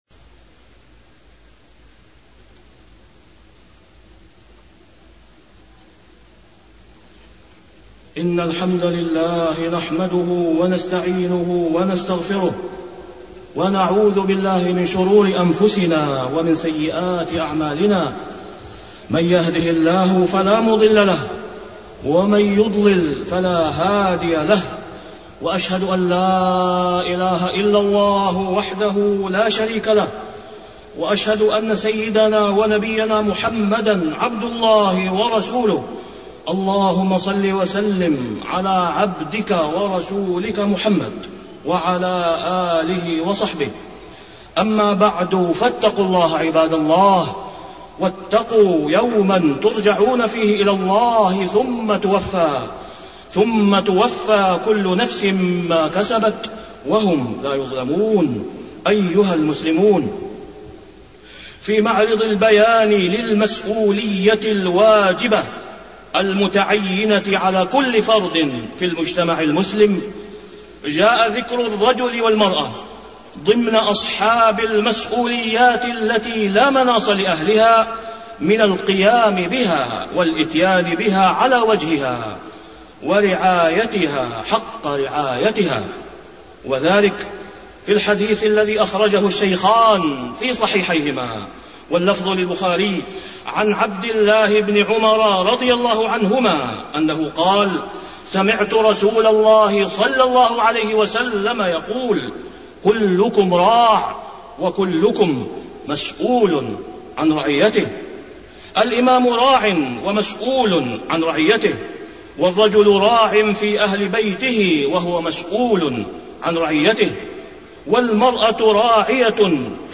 تاريخ النشر ١٩ ربيع الثاني ١٤٢٩ هـ المكان: المسجد الحرام الشيخ: فضيلة الشيخ د. أسامة بن عبدالله خياط فضيلة الشيخ د. أسامة بن عبدالله خياط كلكم راع ومسؤول عن رعيته